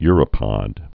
(yrə-pŏd)